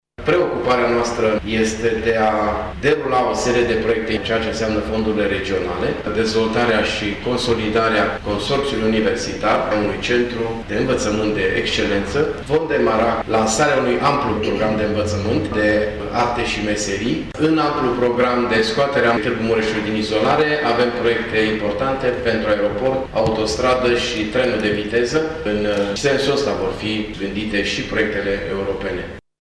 Întâlnirea a avut loc în sala de protocol a Primăriei.